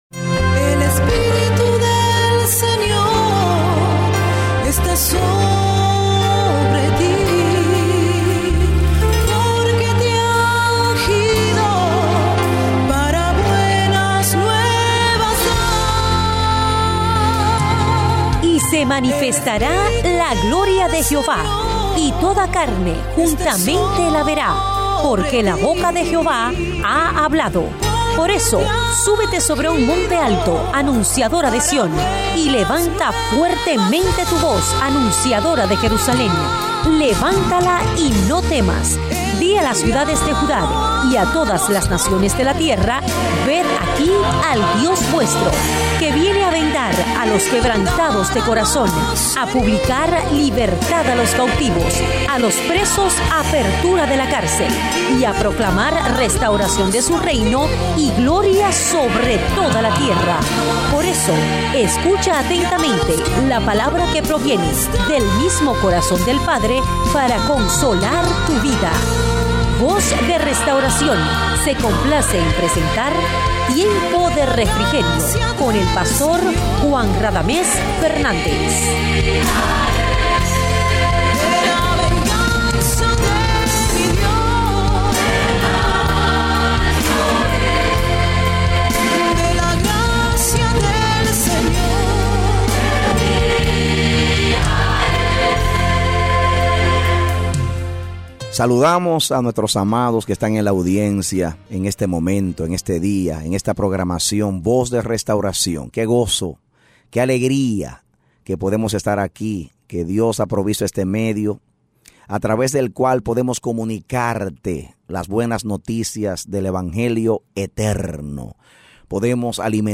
A mensaje from the serie "Programas Radiales."